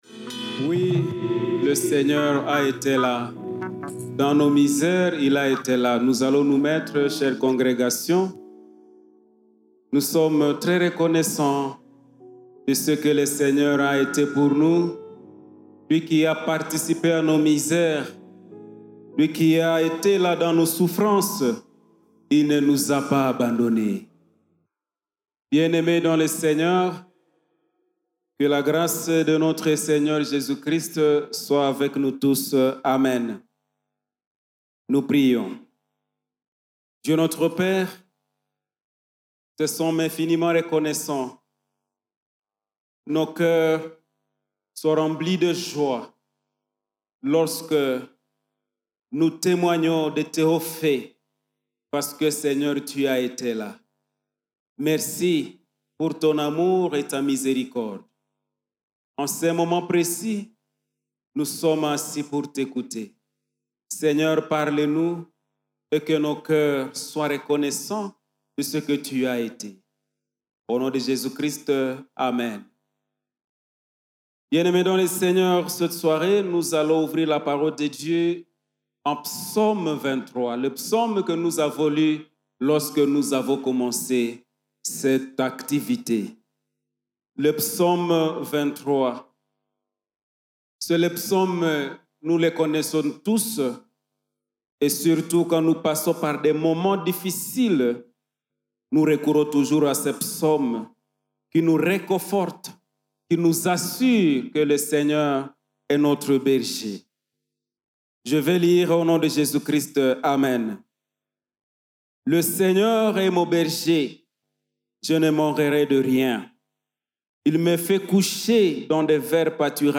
Predications